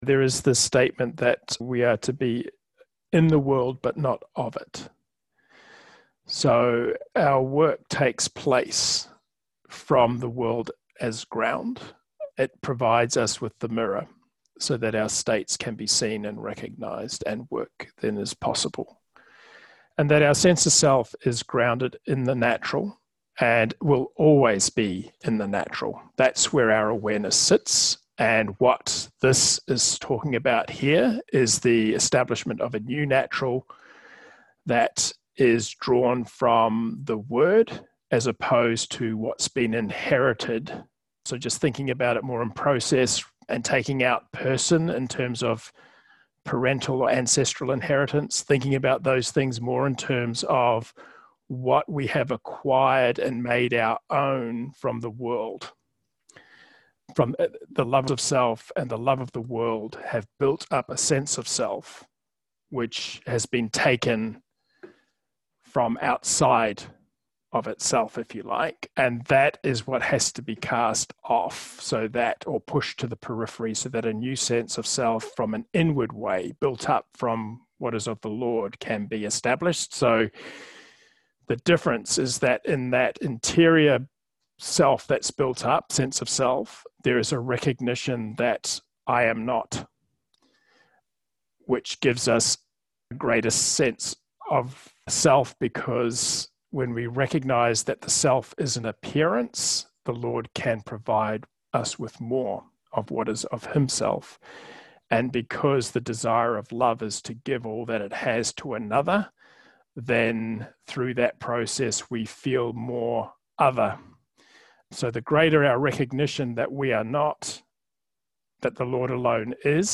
Third Round posts are short audio clips taken from Round 3 comments offered in the online Logopraxis Life Group meetings. The aim is to keep the focus on understanding the Text in terms of its application to the inner life along with reinforcing any key LP principles that have been highlighted in the exchanges.